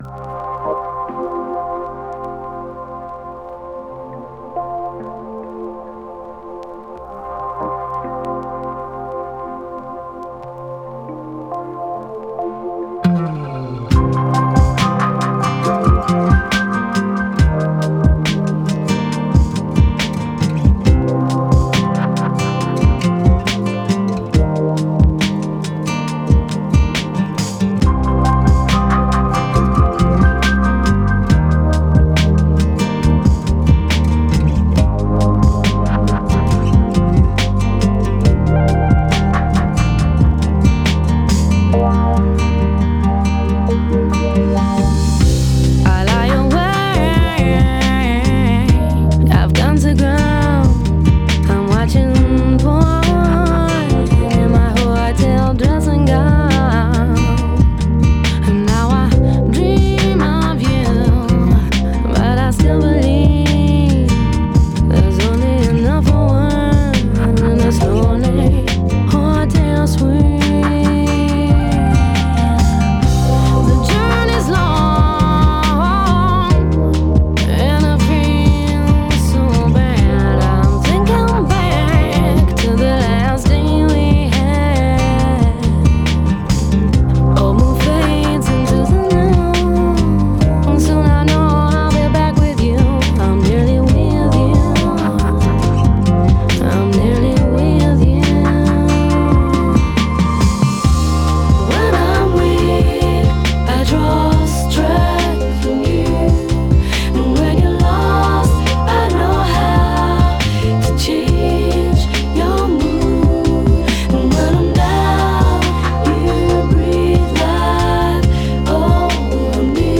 Downtempo